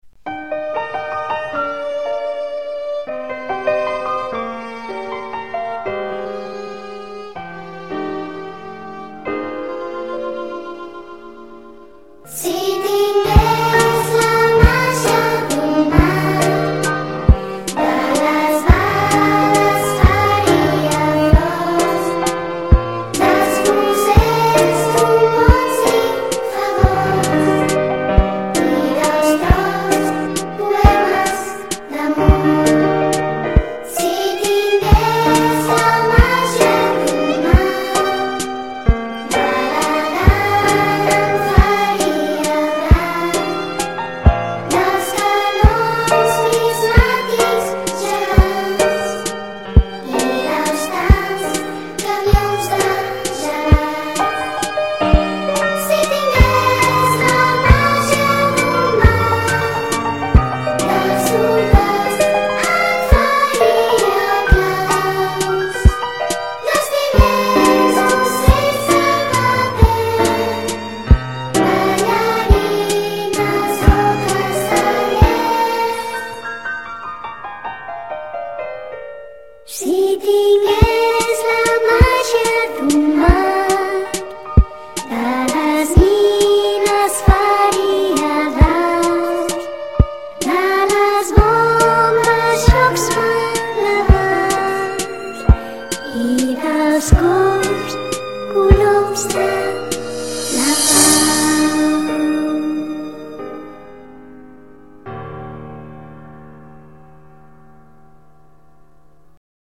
(música adaptada de l’original)